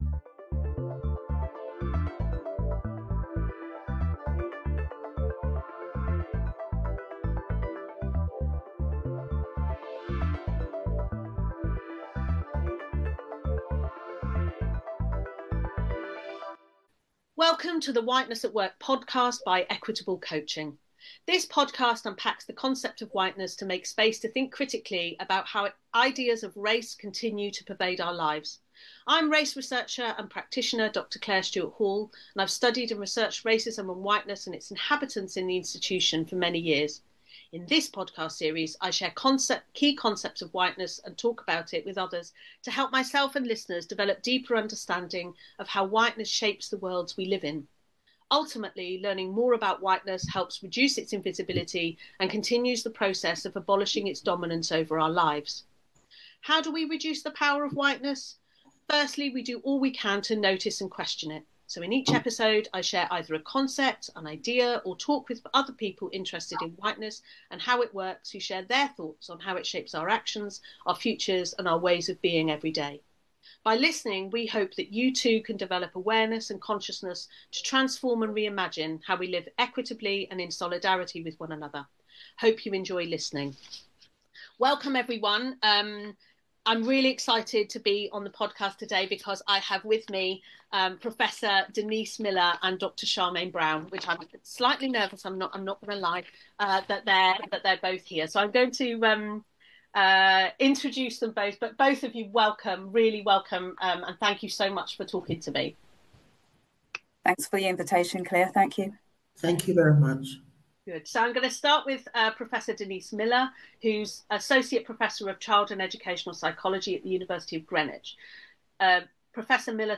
They discuss the mechanisms to dismantle whiteness, the role of research in advocating for change, and the psychosocial costs of racism faced by staff in educational institutions. The conversation emphasises the importance of awareness, community empowerment, and the need for meaningful research that impacts society beyond academia.